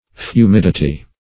Search Result for " fumidity" : The Collaborative International Dictionary of English v.0.48: Fumidity \Fu*mid"i*ty\, Fumidness \Fu"mid*ness\n. The state of being fumid; smokiness.